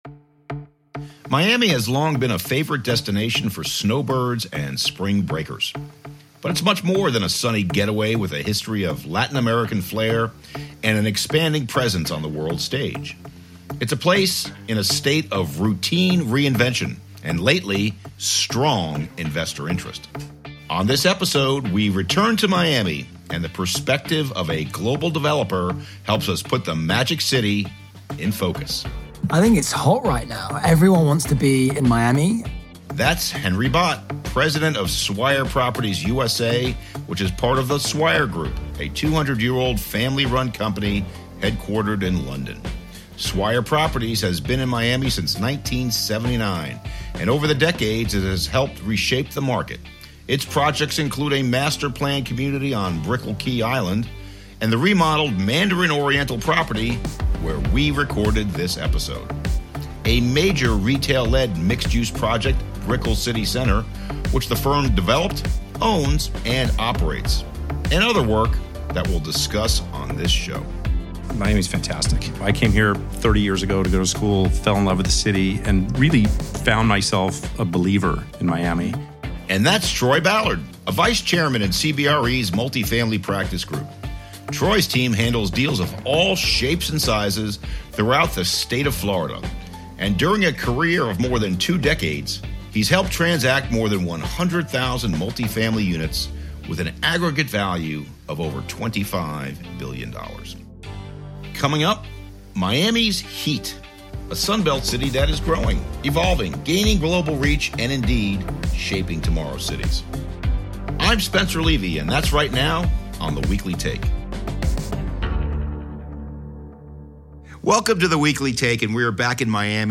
What matters most right now in Commercial Real Estate. Business leaders join economic, industry and subject matter experts to share their distinct views and latest thinking.